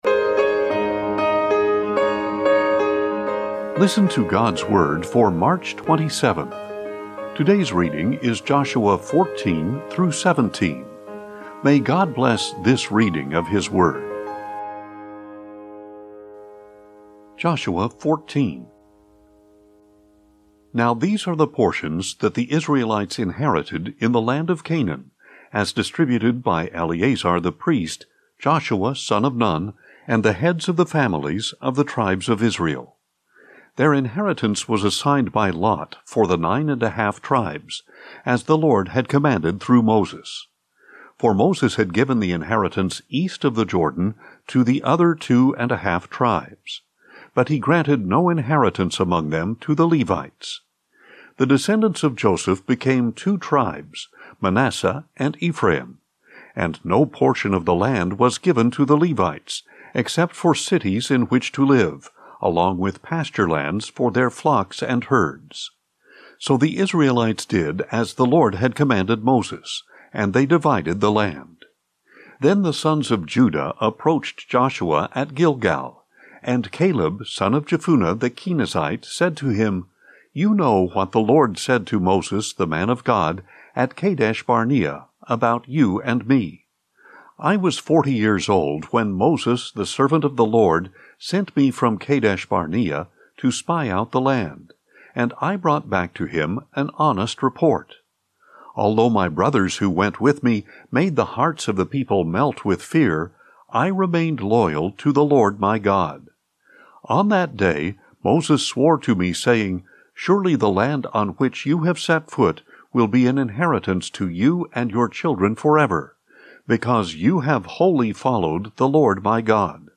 Daily Bible Reading for March 27